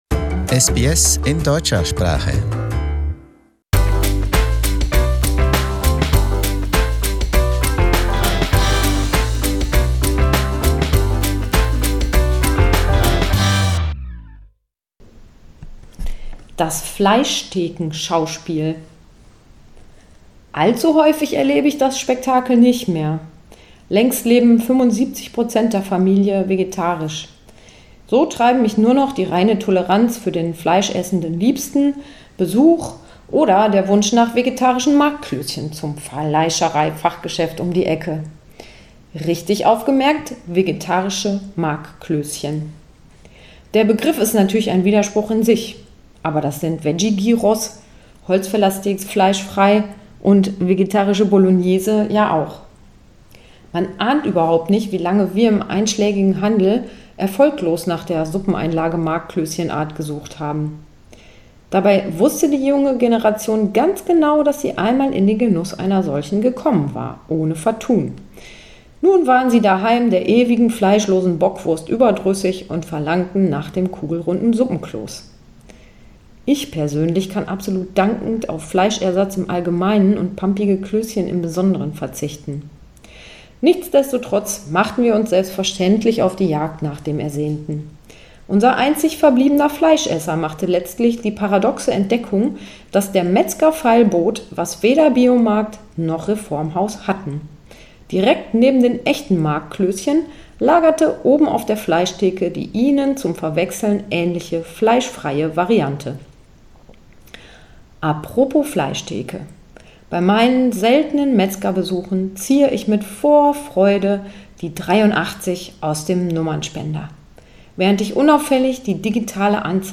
Autorenlesung: Das Fleischklöschenschauspiel